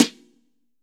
B.B SN 8.wav